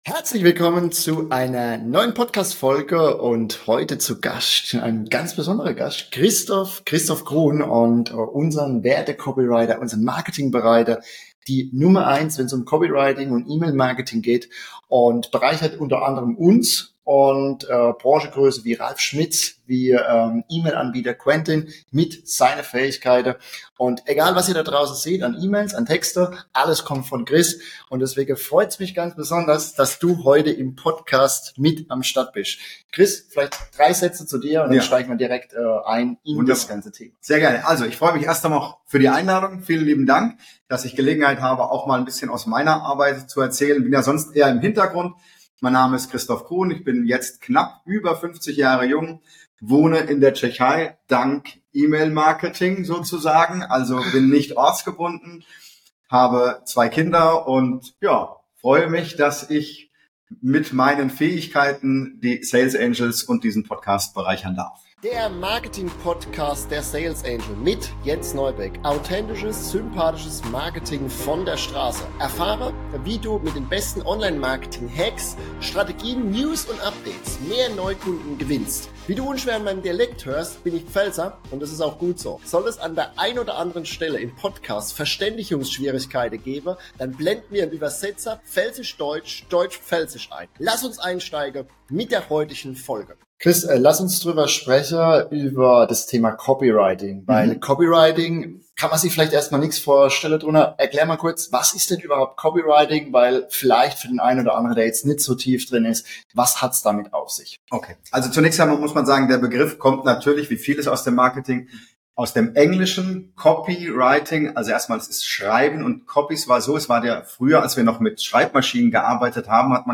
#7 Email Marketing & Copywriting (Interview